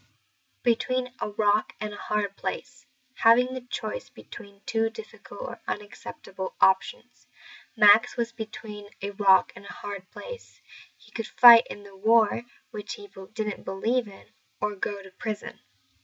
英語ネイティブによる発音は下記のリンクをクリックしてください。